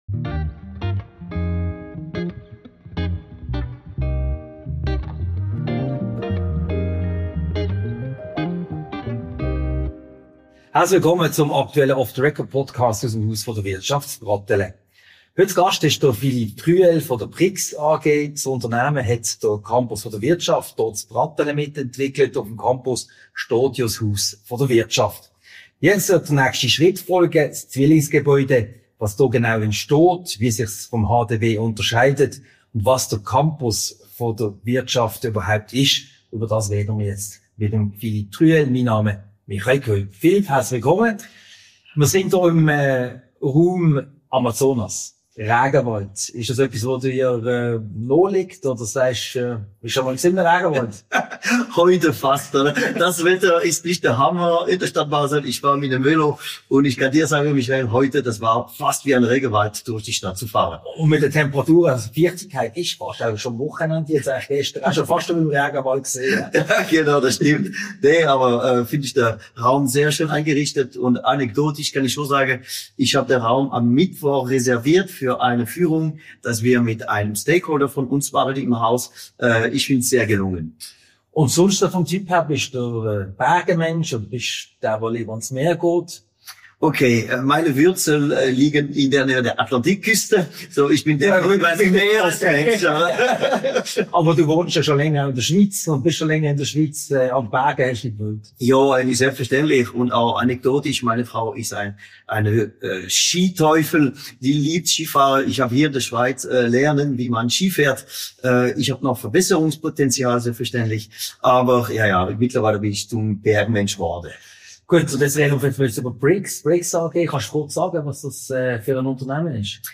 Diese Podcast-Ausgabe wurde im Raum Amazonas im Haus der Wirtschaft HDW aufgezeichnet.